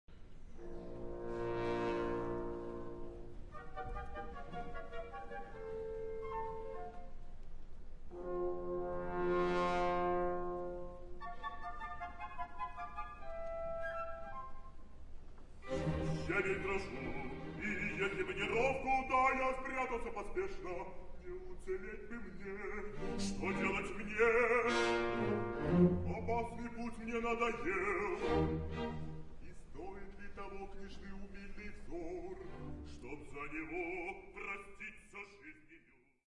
Rondo